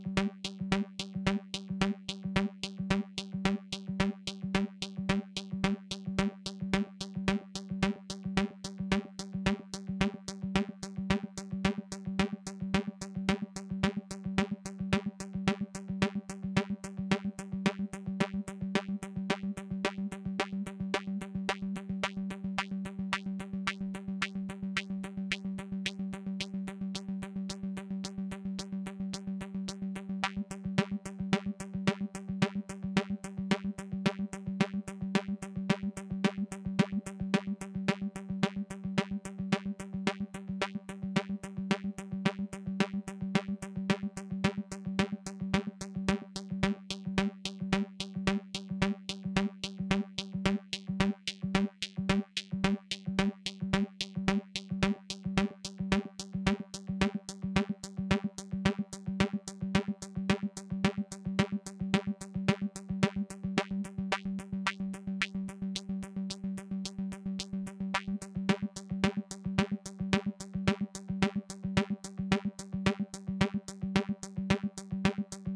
Variando i valori tra CV1, che è l'inviluppo, e CV2 che è l'onda quadra dell'LFO, ci son o interessanti cambi.
Aumentando il CV2 ci si sposta da un mix tra la forma d’onda dell’inviluppo a una quadra pura, fino a quando rimane solo la quadra stessa.